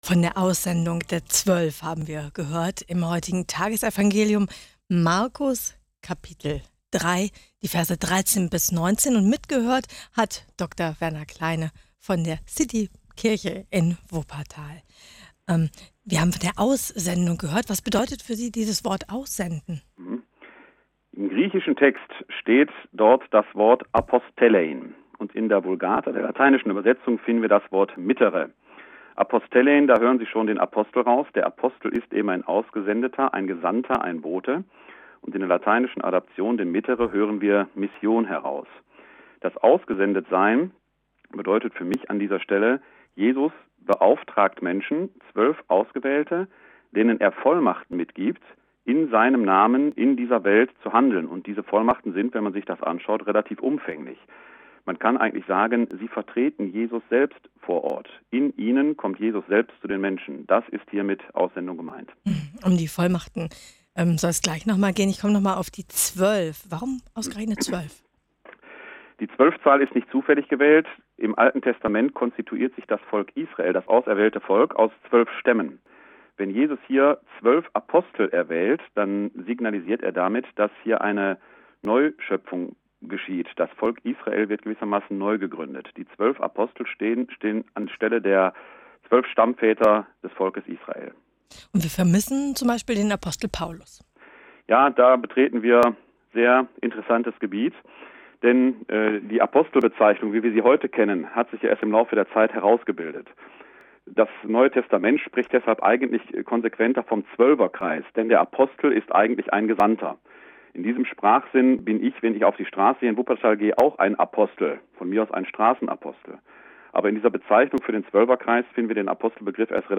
Auslegung